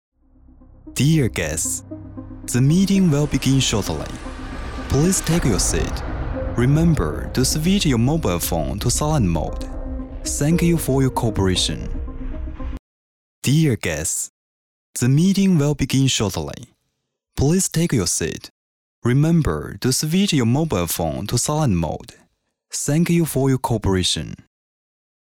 男英29
男英29_外语_国内英语_会议开场词I.mp3